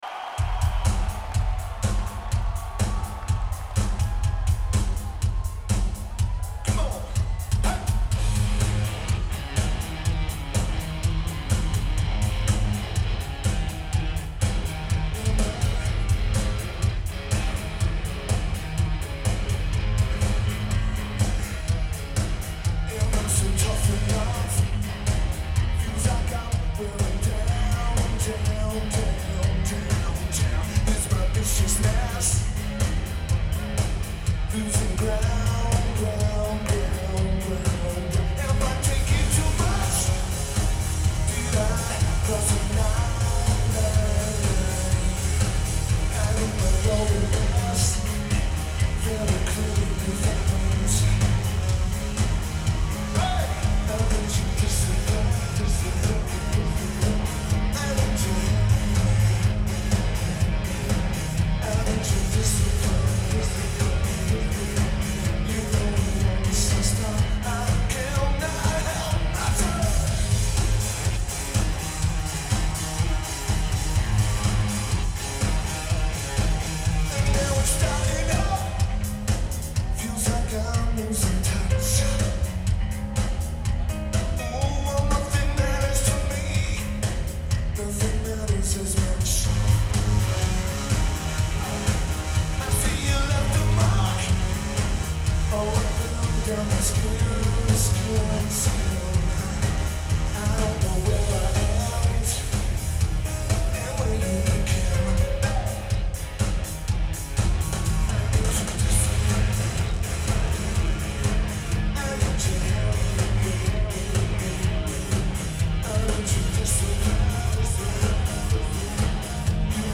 Gwinnett Arena
Lineage: Audio - AUD (Sanon H4 + Internal Mics)
Great recording.